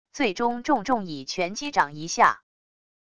最终重重以拳击掌一下wav下载